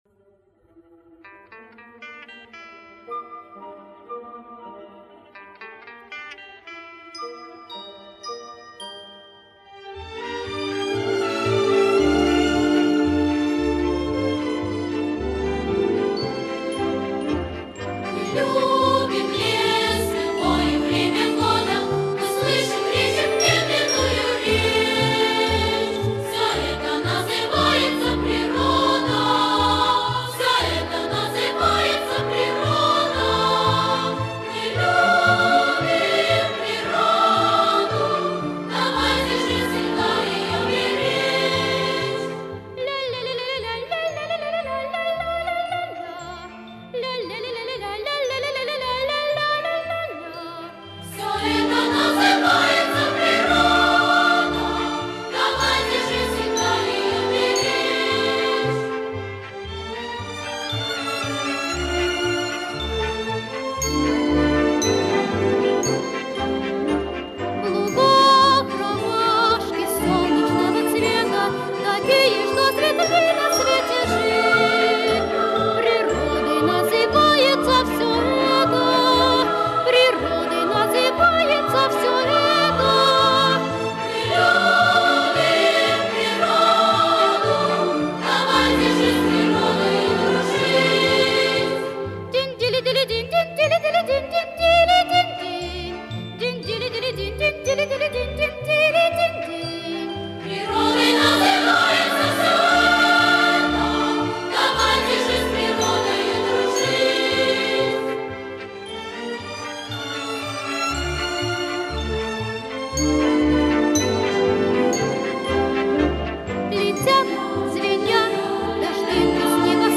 Четвертая часть кантаты